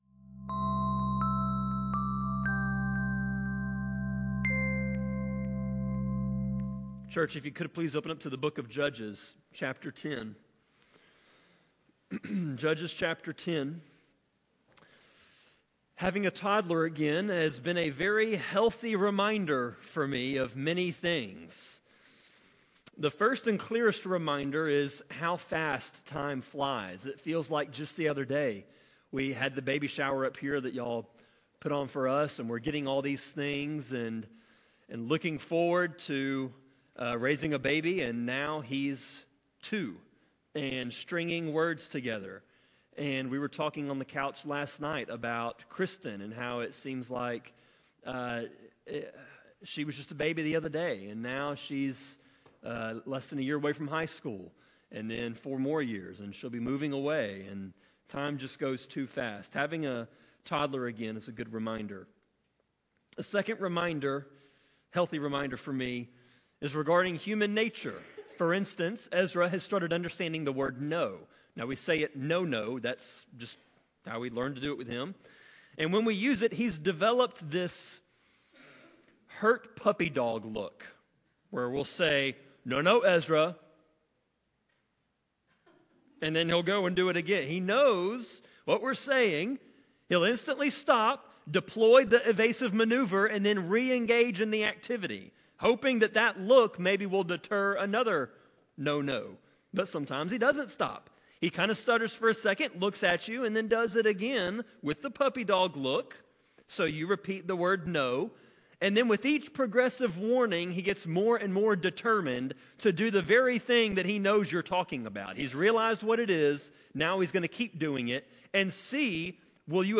Sermon-26.1.11-CD.mp3